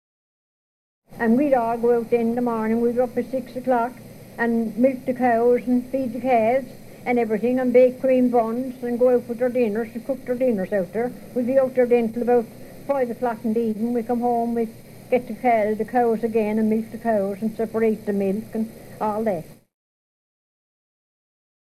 Newfoundland Irish English (conservative rural speaker of Irish background) — 1
Features of conservative rural Newfoundland Irish English (audible in the above sound files)
1) Alveolar stop realisation of TH and DH, e.g. there [de:r].
2) Very open realisation of back vowels, e.g. morning [mɒ:rnɪn], clock [klɒk].
3) Centralised onset for AI-diphthong before voiceless obstruents, e.g. night [nəɪt], but not to the same extent before voiced ones, e.g. five [fɐɪv].
4) Alveolar realisation of syllable-final /l/, e.g. all [ɒ:l].